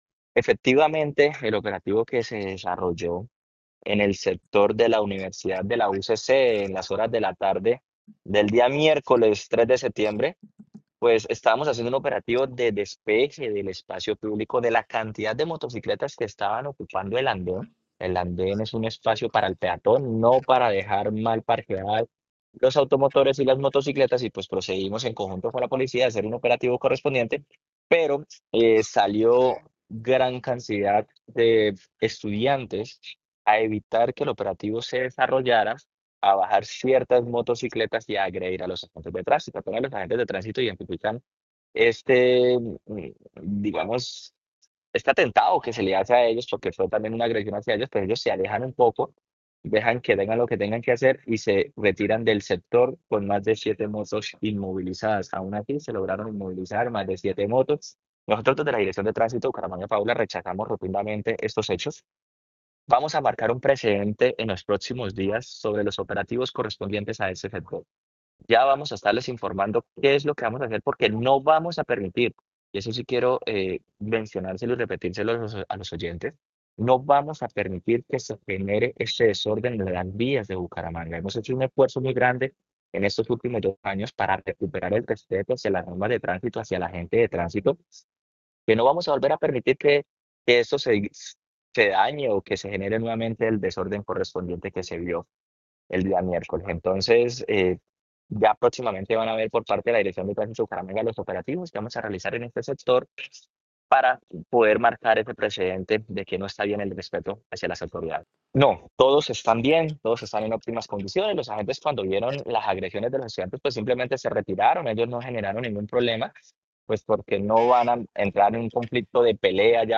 Jhair Manrique, director de Tránsito de Bucaramanga